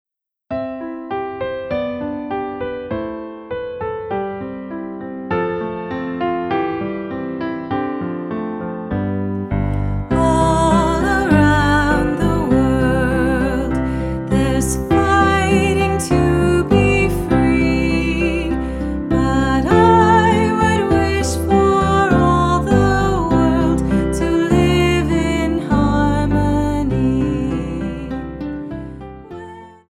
unison/two-part choral arrangement